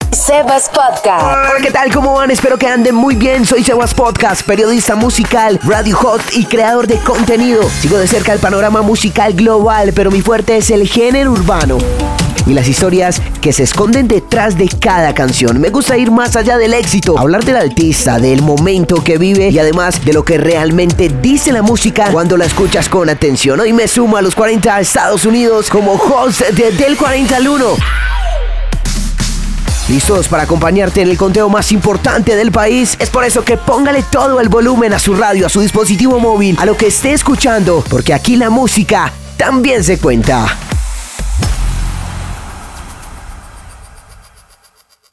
Los 40 USA Demo
Spanish Hits, Spanish Contemporary, Urban, Reggaeton, Trap, Mexican Regional